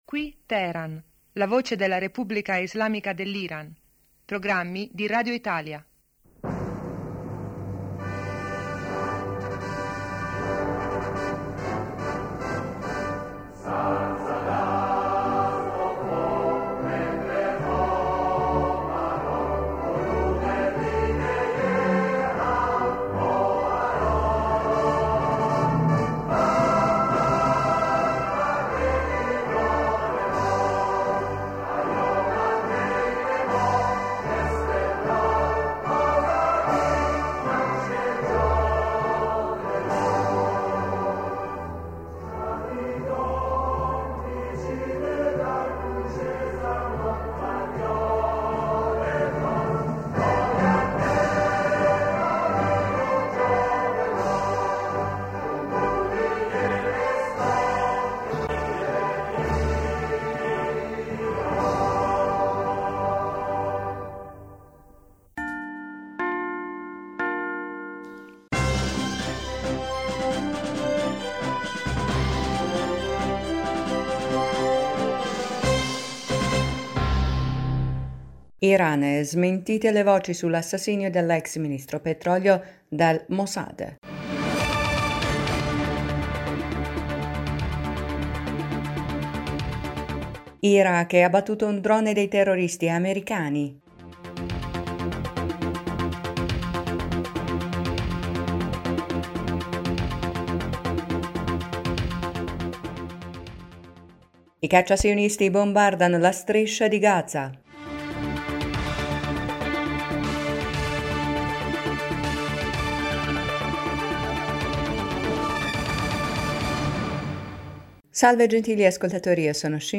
Ecco i titoli più importanti del nostro radiogiornale: 1-Iran, smentite le voci sull’assassinio dell’ex-ministro petrolio dal Mossad,2-Iraq, abbattuto un d...